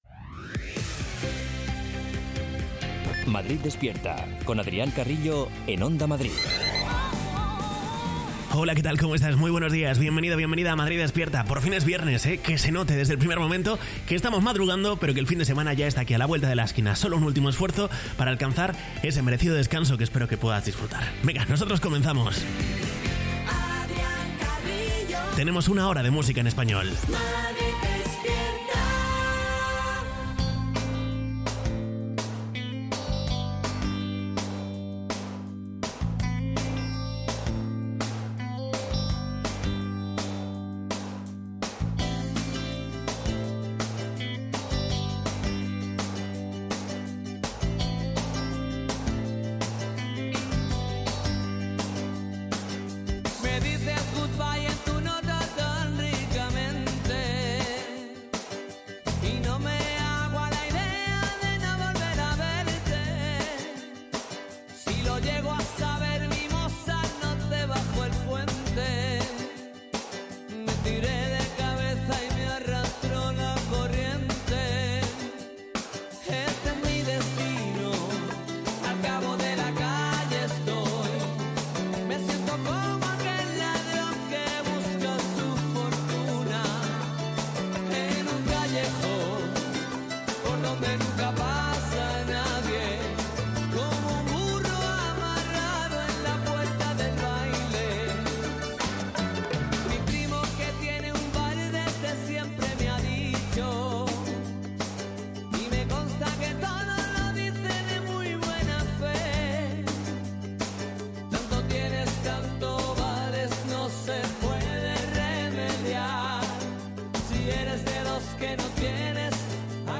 Morning show
con la mejor música